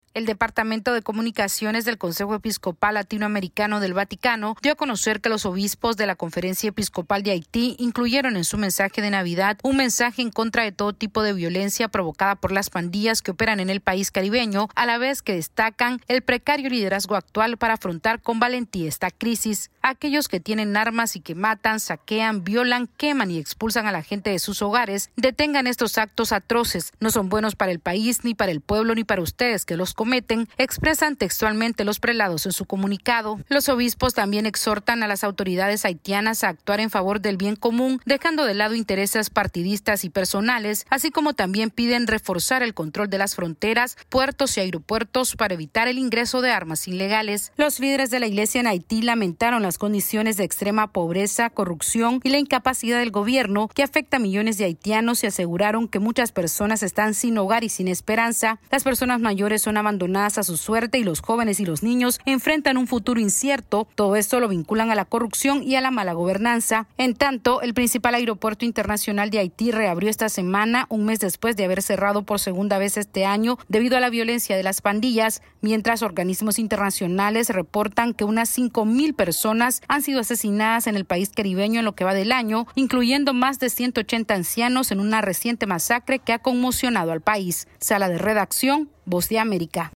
AudioNoticias
Con motivo de la Navidad, la Conferencia Episcopal de Haití, envió un mensaje dirigido a la comunidad internacional, las autoridades locales y la población en general, clamando unidad para acabar con la violencia perpetrada por las pandillas. Esta es una actualización de nuestra Sala de Redacción.